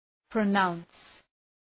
Προφορά
{prə’naʋns}